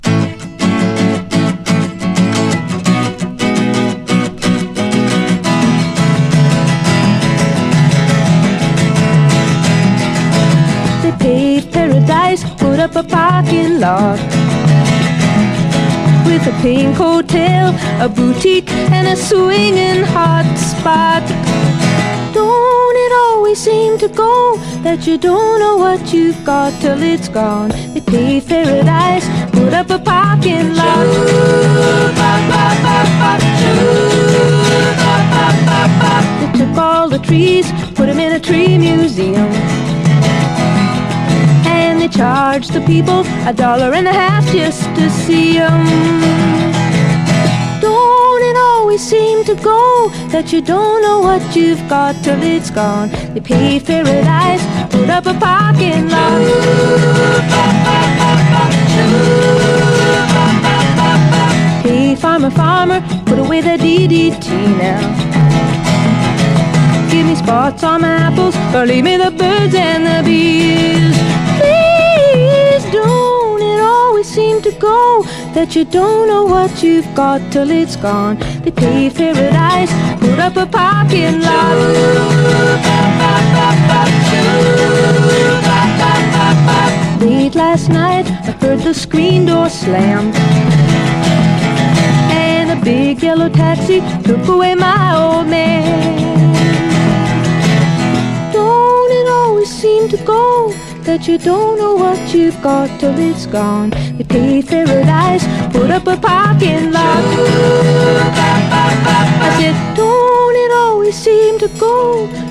SSW / AOR, ROCK, 7INCH